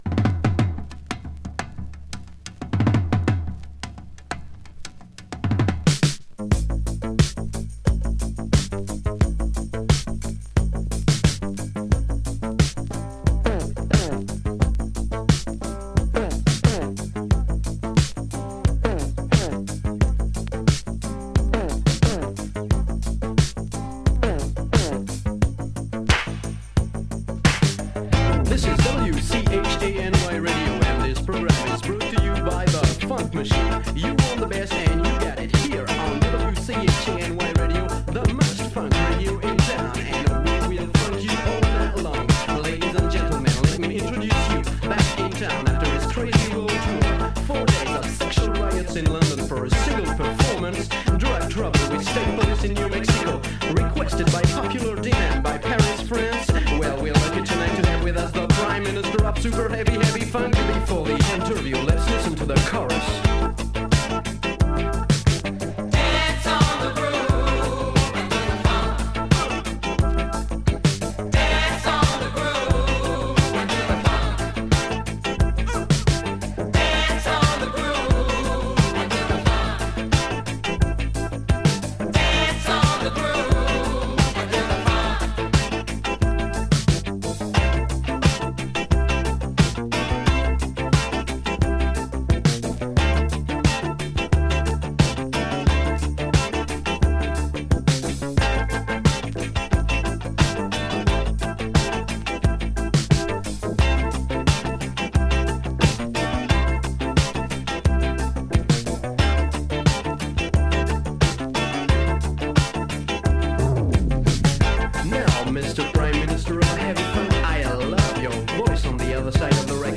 COSMIC CLASSIC!
cosmic disco